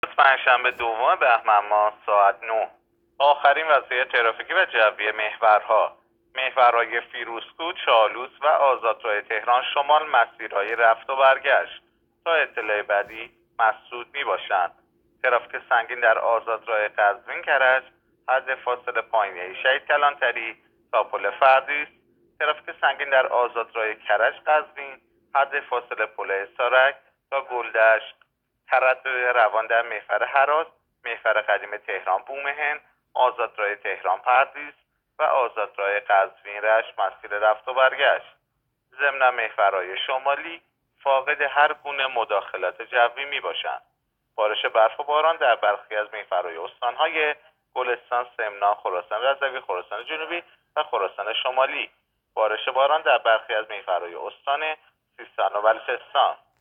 گزارش رادیو اینترنتی از آخرین وضعیت ترافیکی جاده‌ها ساعت ۹ دوم بهمن؛